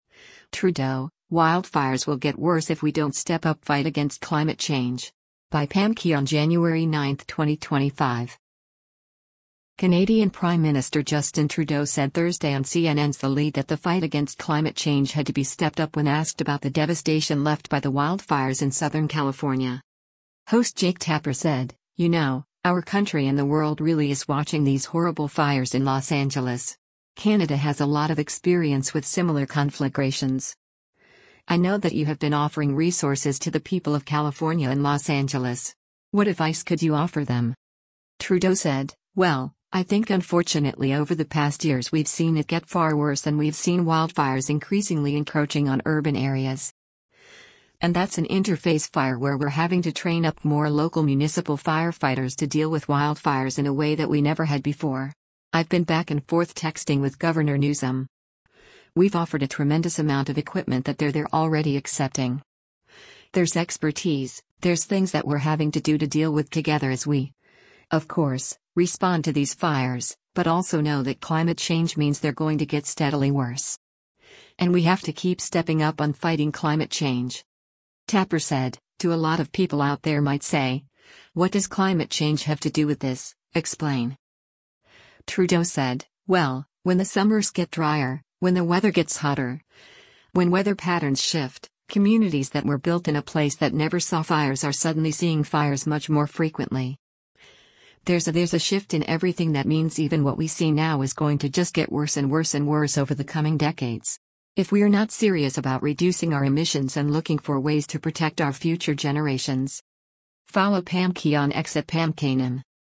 Canadian Prime Minister Justin Trudeau said Thursday on CNN’s “The Lead” that the fight against climate change had to be stepped up when asked about the devastation left by the wildfires in Southern California.